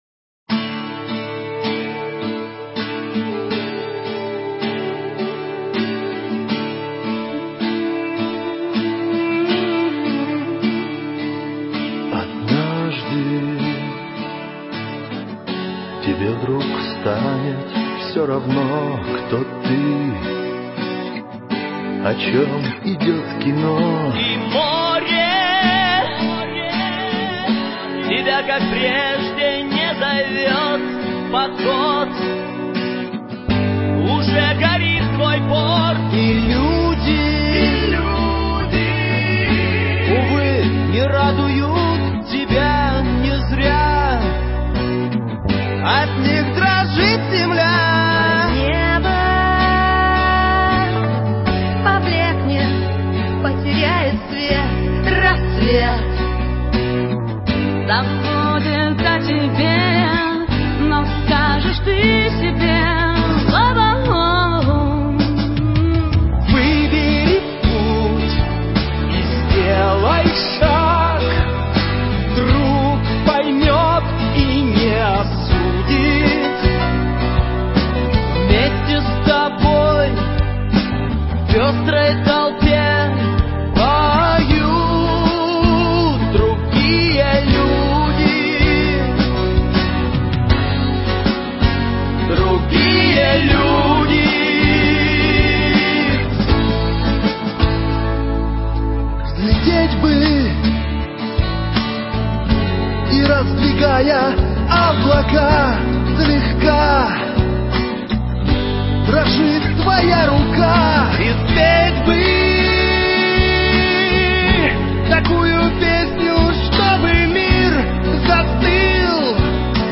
баллада записаная десятком наших рокеров